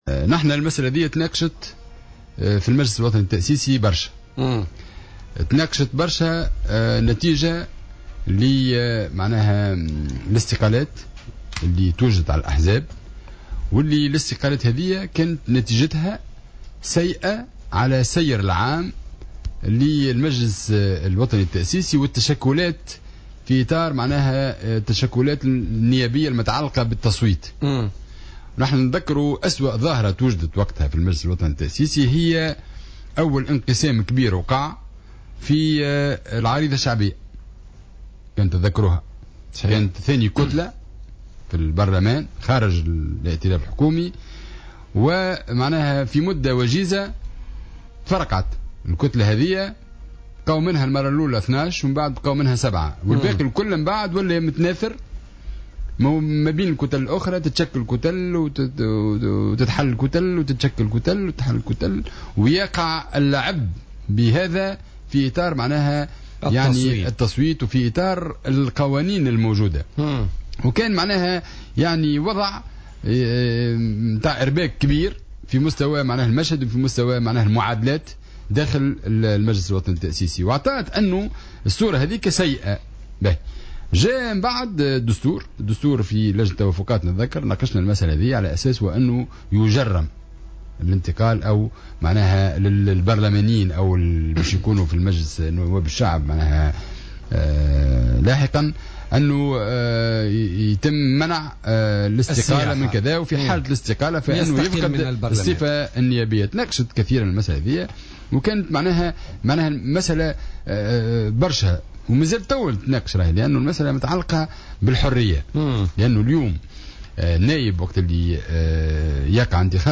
وأضاف الرحوي، ضيف برنامج "بوليتيكا" اليوم الثلاثاء أن هذه المسألة مازلت محل نقاش إلى الآن، مشيرا إلى أن التحاق أي نائب داخل البرلمان بكتلة أخرى يدخل من باب الحرية ولا يمكن للحزب أن يمنع ذلك باعتبار وأنه مفوض من الشعب للدفاع عنه والتحدث باسمه وليس من الحزب.